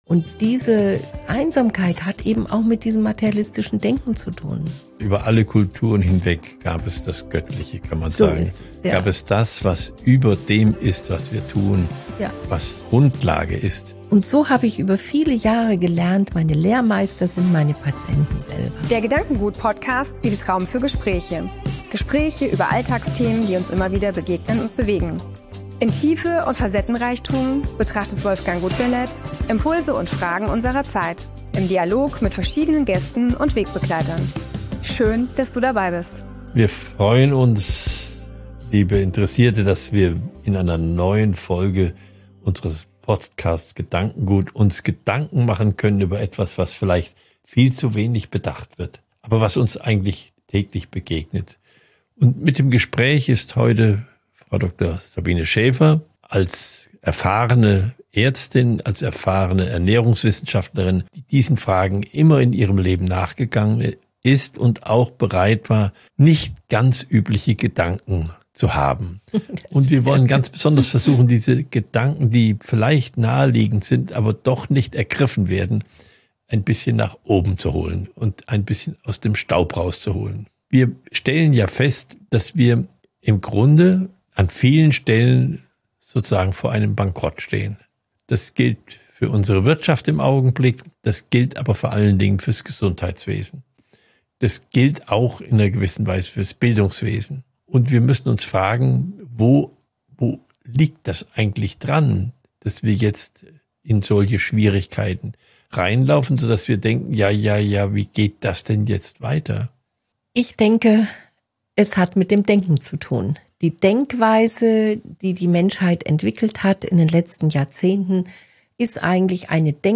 Ein Gespräch über Ernährung, Gemeinschaft, Esskultur und die Kraft der kleinen Schritte.